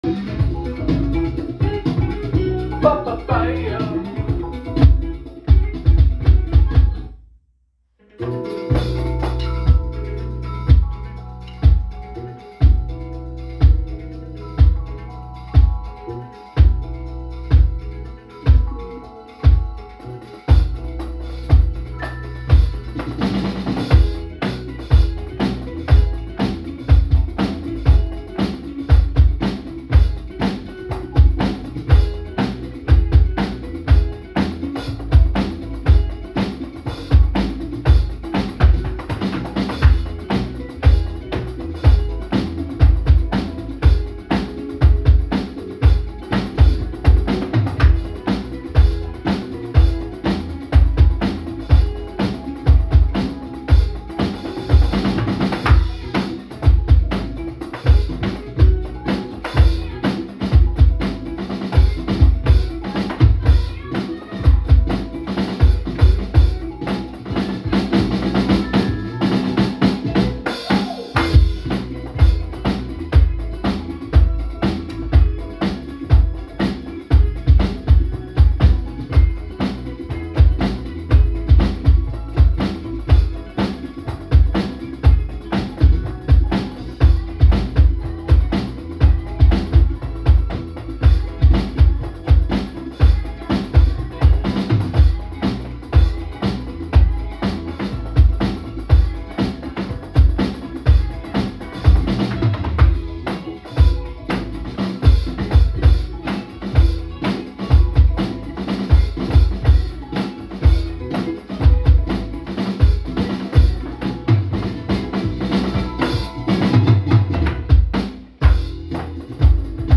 here's a shitty recording of me trying to learn how to modulate between son clave and straight time
I apologize for sound quality and skill level I dropped my stick 4 times trying to delay hits